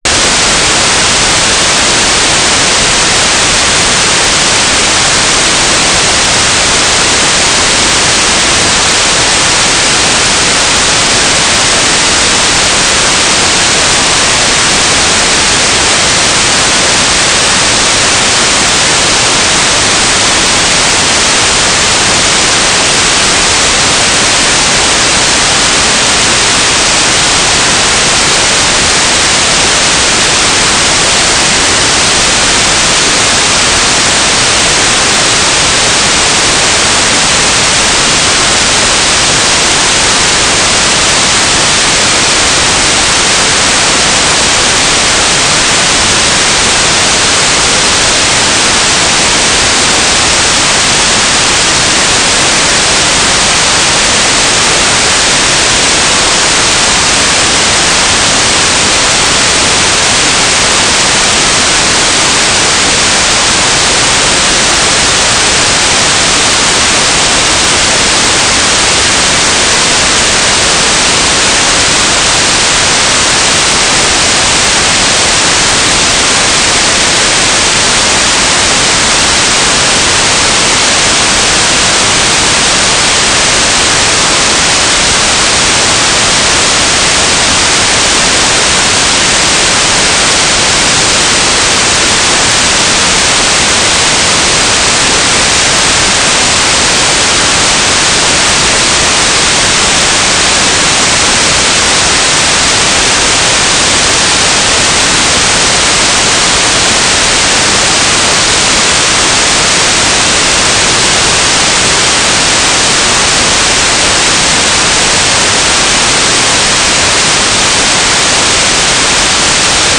"transmitter_description": "Mode U - GFSK9k6 - AX.25 G3RUH",